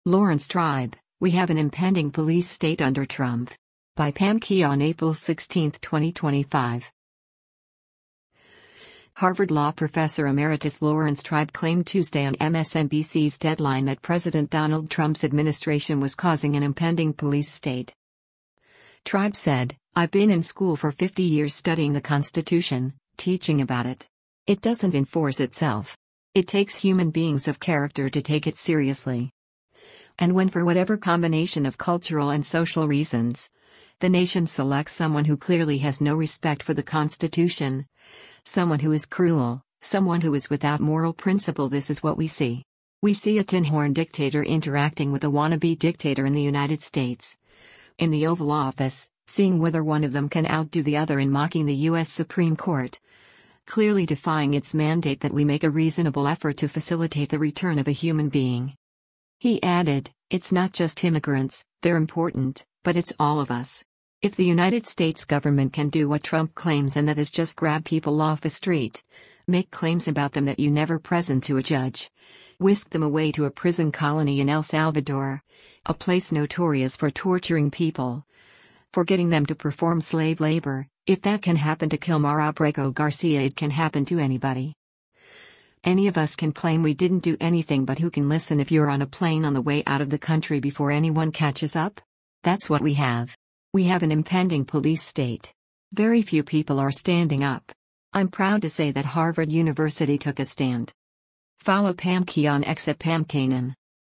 Harvard Law professor emeritus Laurence Tribe claimed Tuesday on MSNBC’s “Deadline” that President Donald Trump’s administration was causing “an impending police state.”